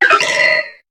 Cri de Pitrouille dans Pokémon HOME.